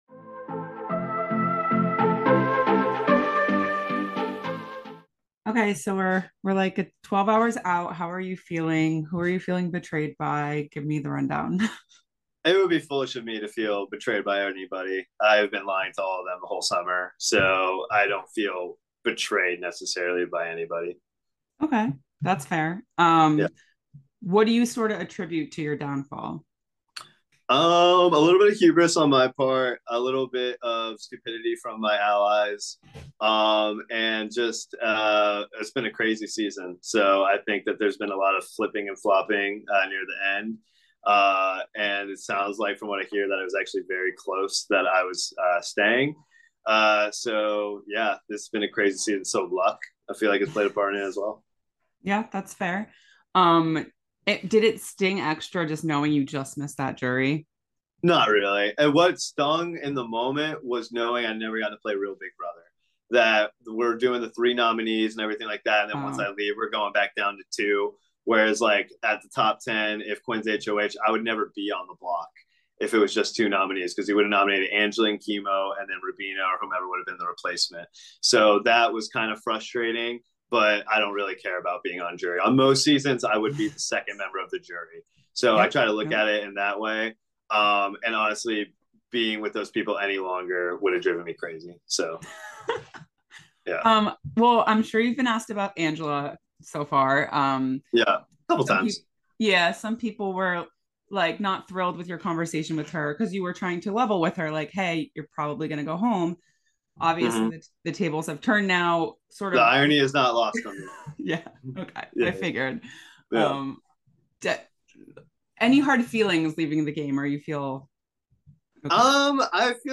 Big Brother 26 Exit Interview